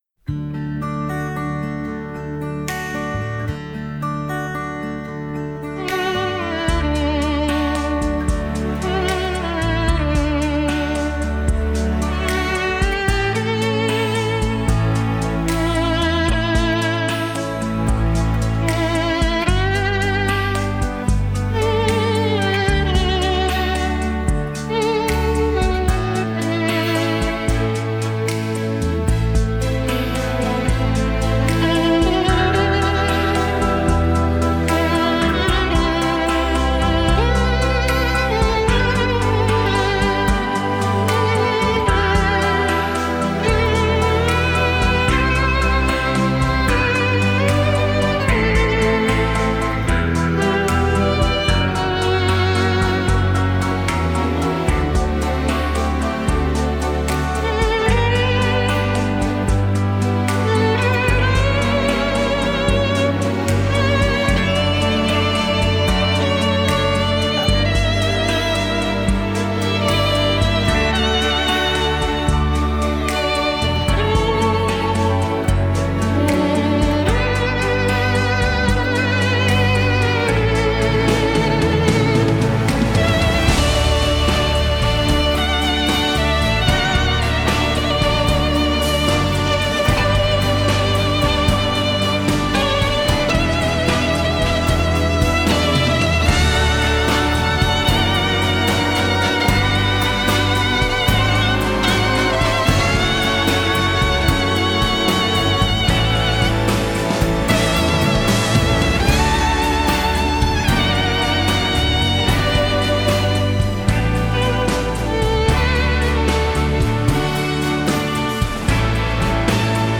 Genre: Score
virtuoses Spiel mit modernen Klängen